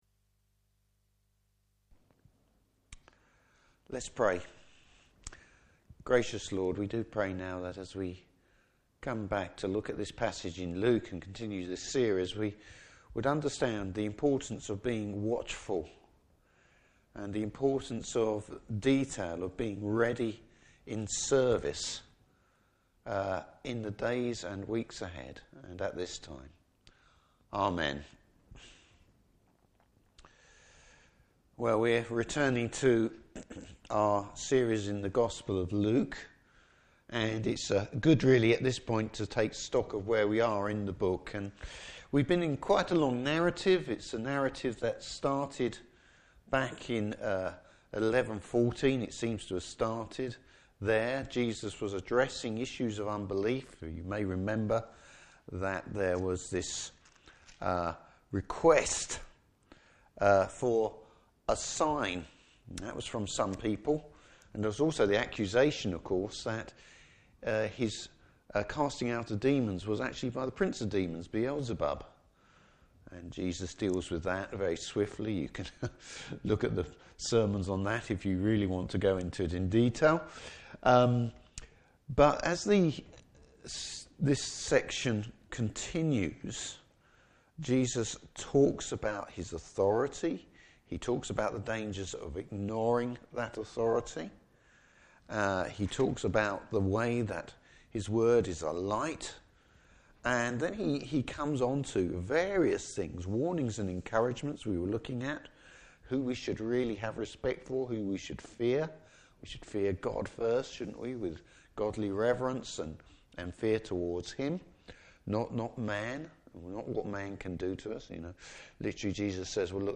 Service Type: Morning Service Bible Text: Luke 12:35-48.